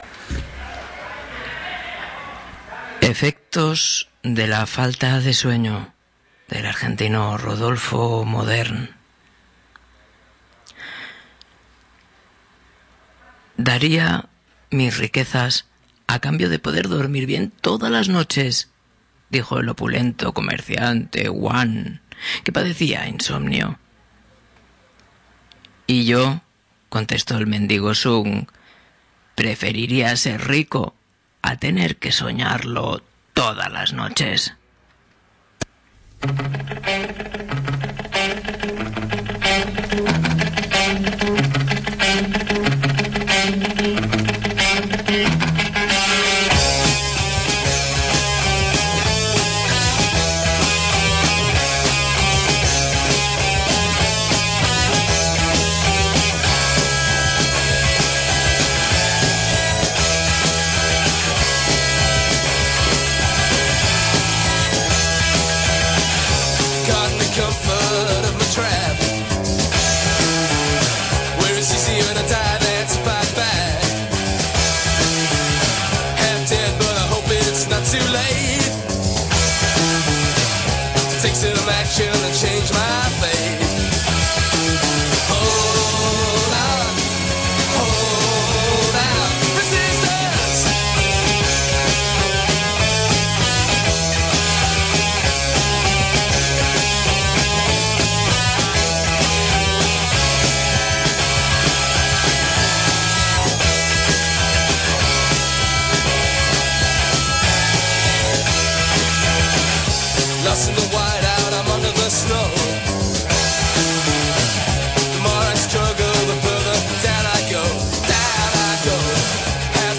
Leemos dispersos por el programa tres relatos breves de tres autores latinoamericanos: un argentino, un venezolano y un cubano.